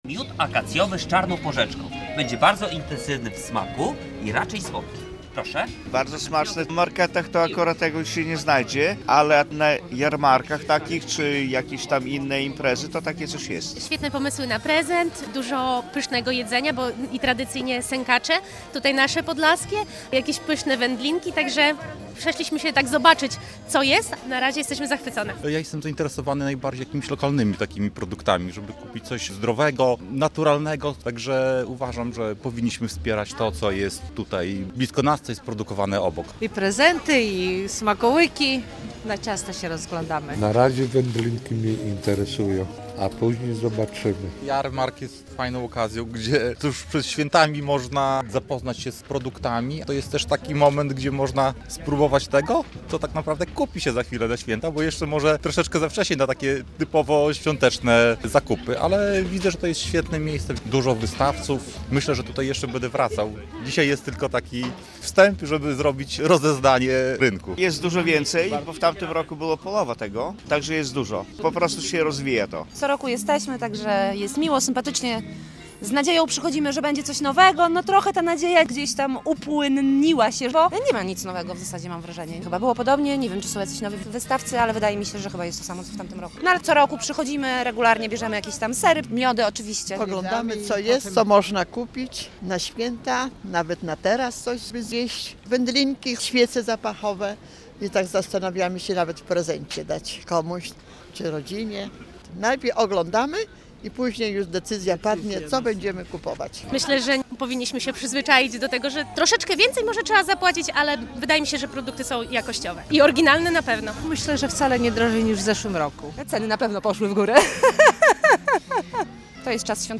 Ponad 60 stoisk ze świątecznym jedzeniem i ozdobami, a także atrakcje dla całych rodzin - w sobotę (29.11) na Rynku Kościuszki oficjalnie otwarto Białostocki Jarmark Świąteczny. Centrum miasta błyskawicznie opanowały tłumy mieszkańców, a wydarzenie uświetnił występ orkiestry dętej.
Ruszył Białostocki Jarmark Świąteczny - relacja